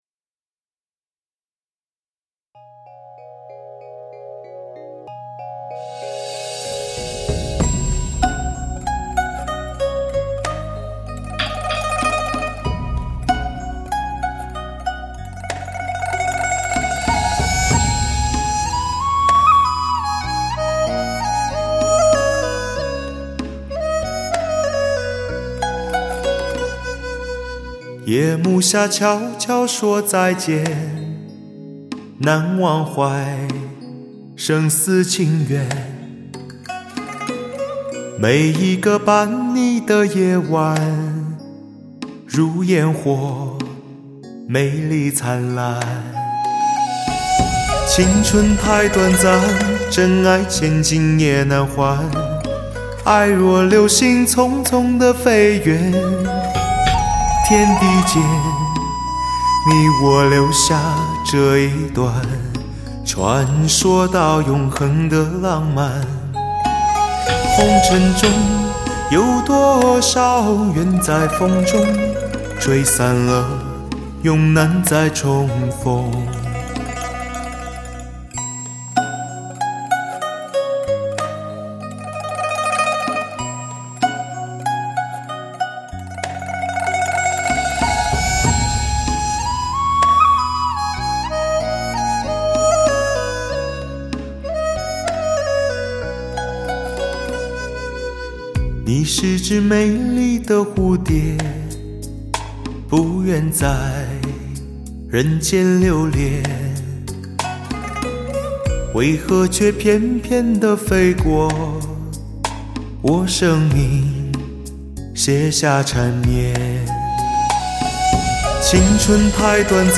高音明亮、中音饱满、低音浑厚有力、感情到位，全碟乐器搭配天衣无缝，质感惊人